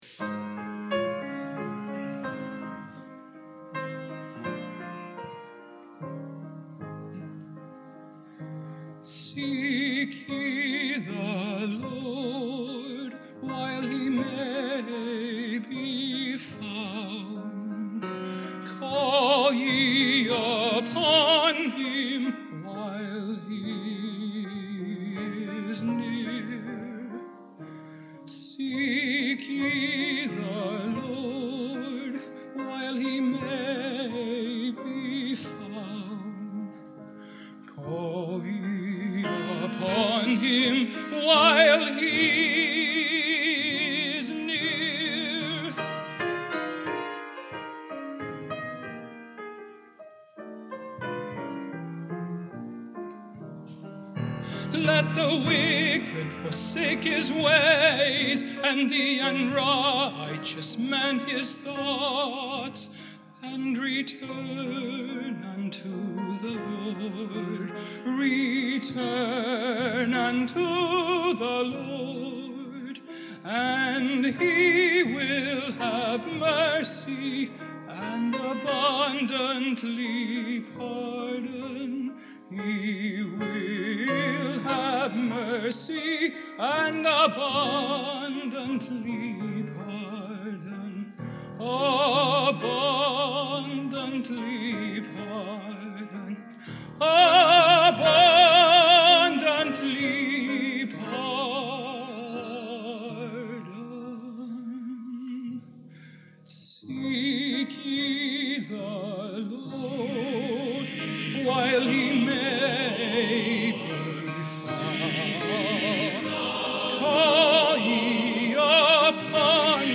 Genre: Modern Sacred | Type: Featuring Hall of Famer